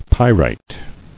[py·rite]